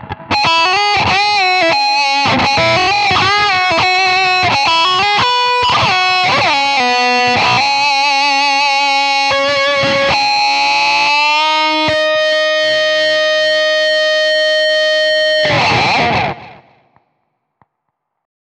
FIXED WAH
特定の周波数を固定して強調する「半止めワウ」。B’zのような鼻詰まり気味で力強いリードトーンを狙う際、EQでは出せない独特のサチュレーション感を得るのに最適です。
B’z風どころかB’zなんですけどそれっぽさは出ているんじゃないかなーと思います。
FIXED-WAH.wav